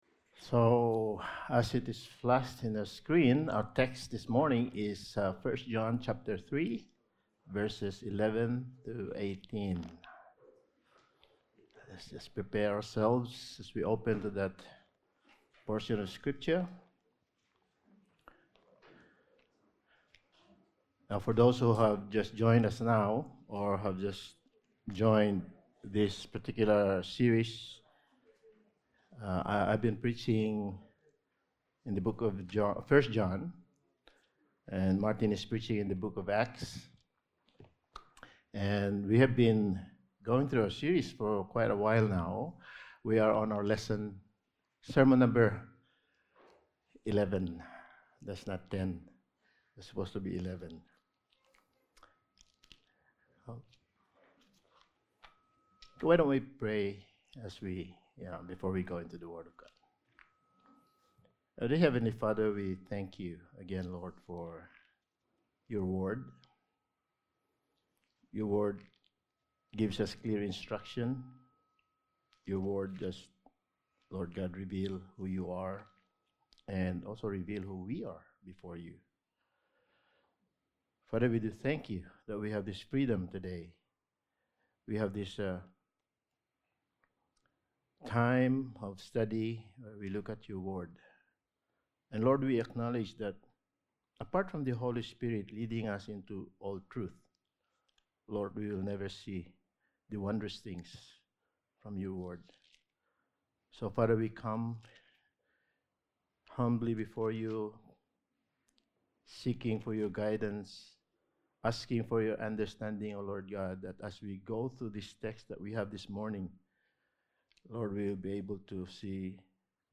1 John Series – Sermon 11: Contrast: Children of God vs. Children of Devil – Part 2
Service Type: Sunday Morning